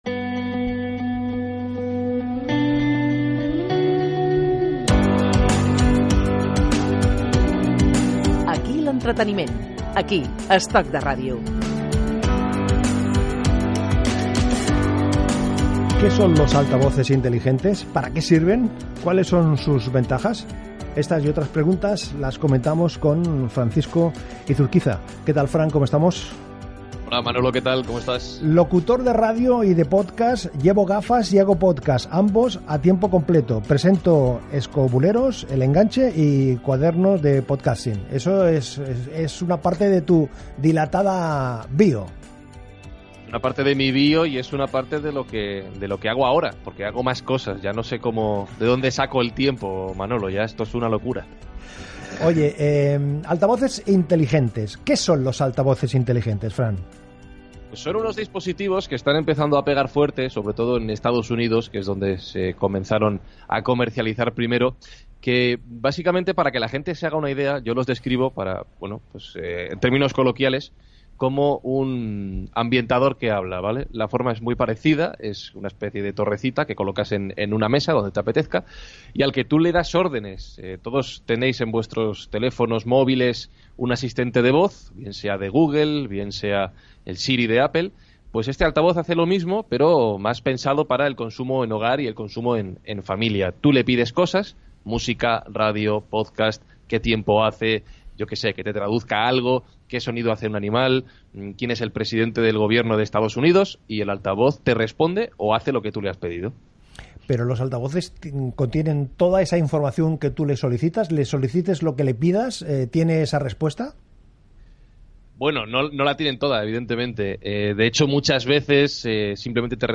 Careta del programa
S'explica què són, què fan i el dispositiu Google Home Gènere radiofònic Entreteniment